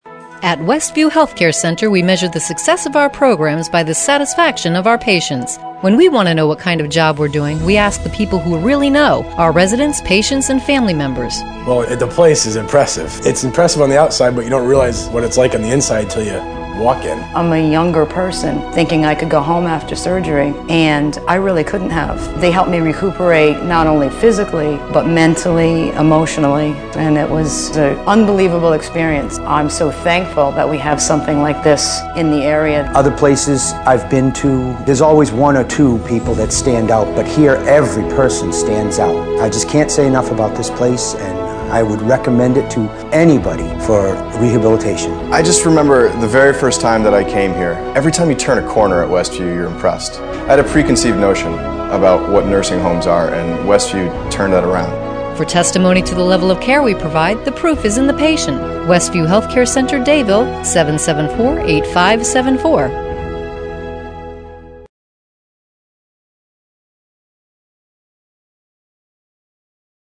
Facility Testimonial
whcc_testimonial.mp3